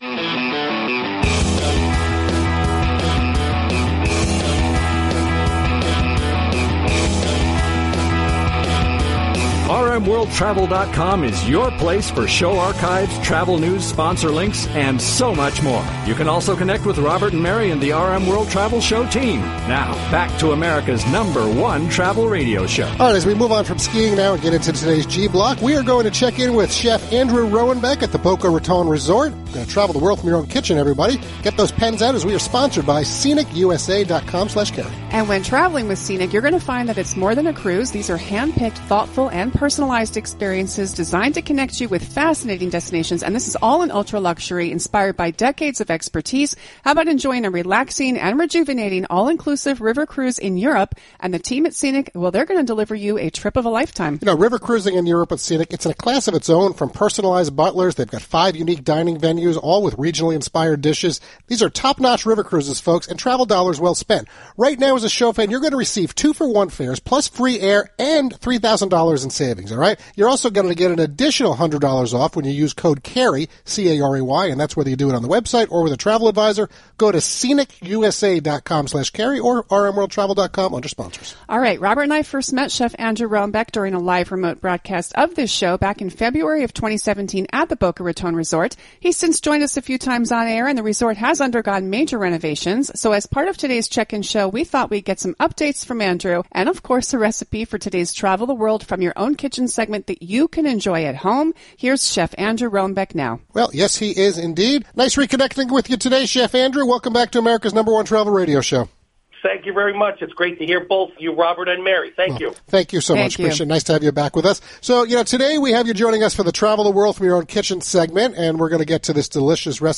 live broadcast of America’s #1 Travel Radio Show
the now archived audio from our live broadcast